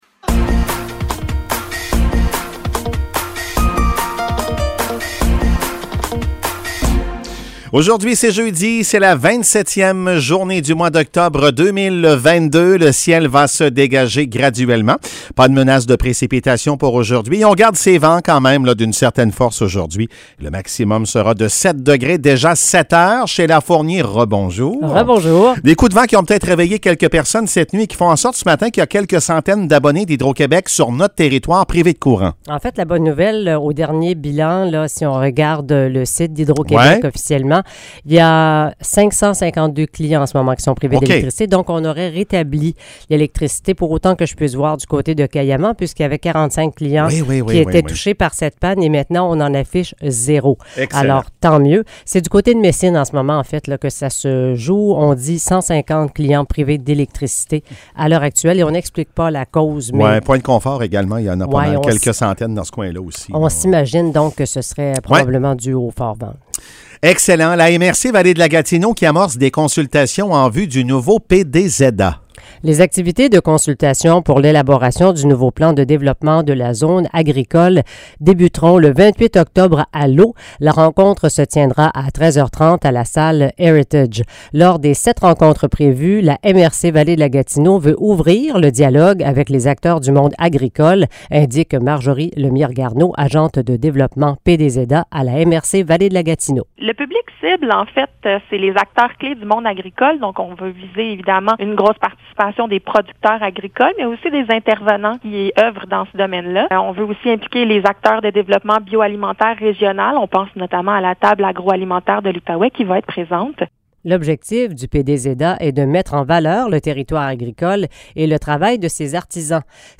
Nouvelles locales - 27 octobre 2022 - 7 h